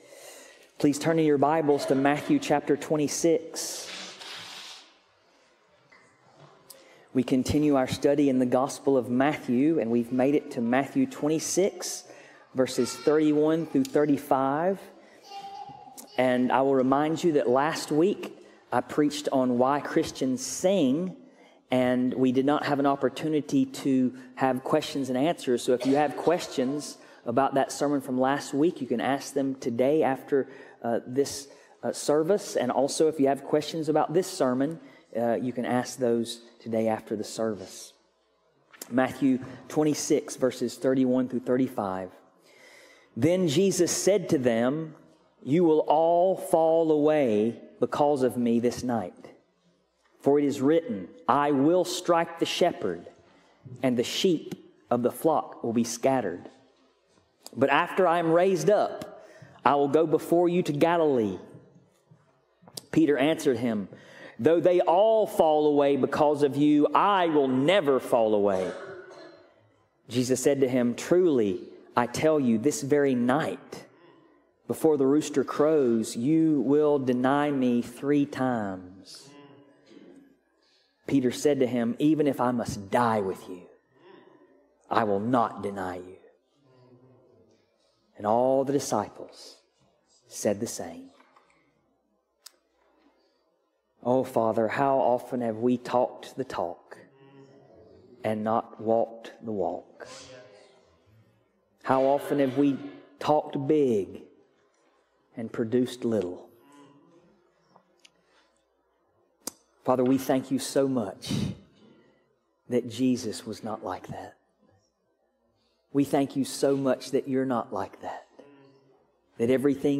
Passage: Matthew 26:31-35 Service Type: Sunday Morning Christ Jesus Never Fell Away Stood Firm In Every Law Obey To All God’s Promises He’s “Yea!”